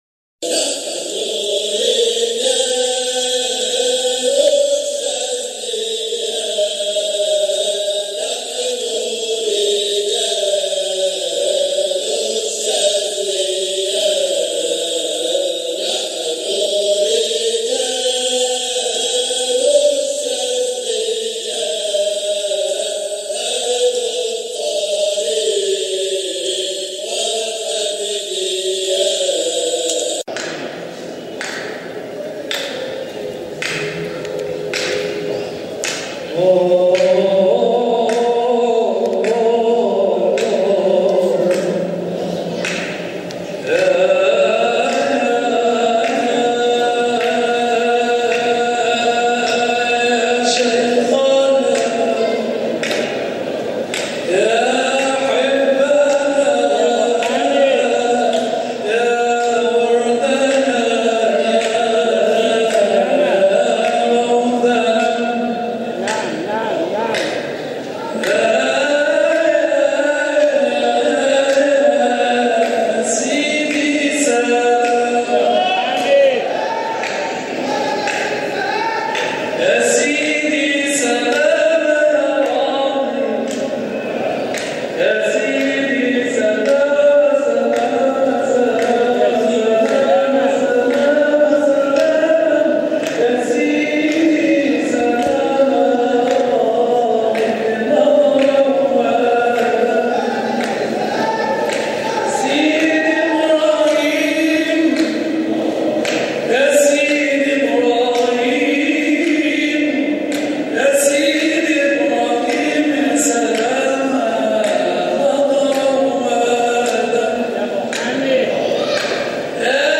جزء من حلقة ذكر بمسجد سيدنا ابراهيم الدسوقي قدس سره 2018